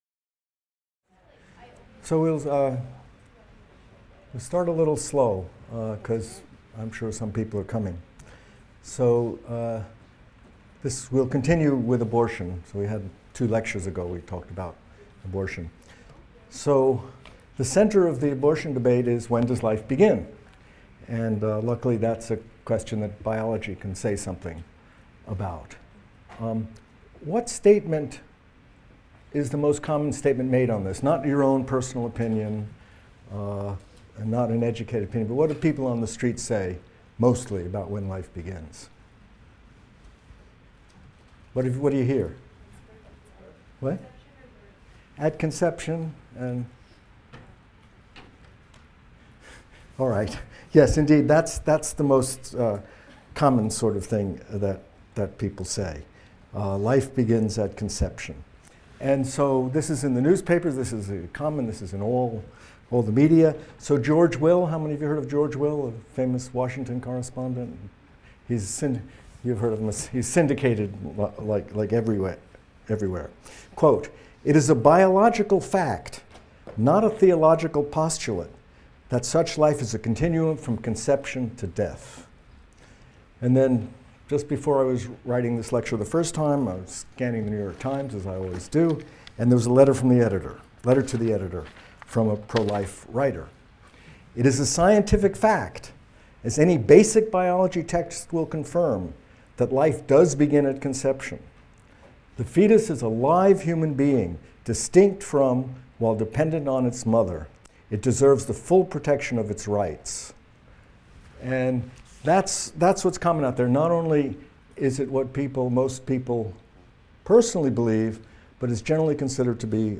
MCDB 150 - Lecture 23 - Biology and History of Abortion | Open Yale Courses